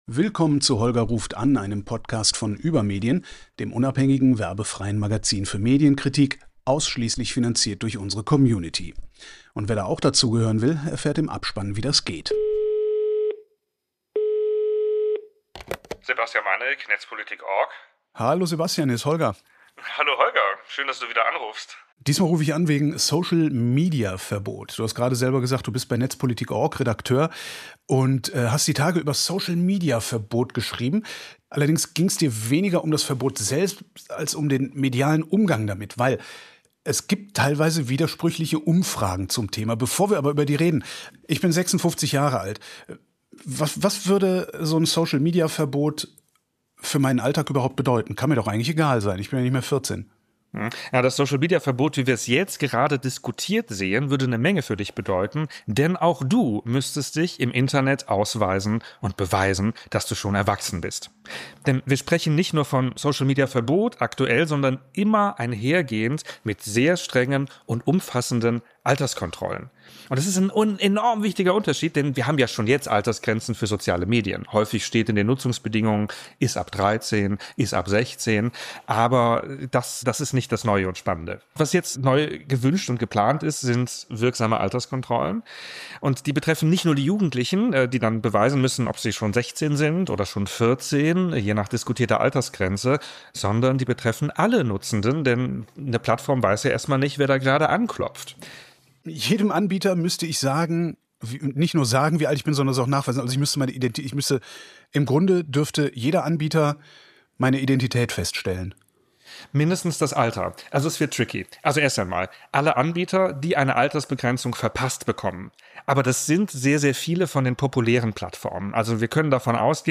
Gast: Tech-Journalist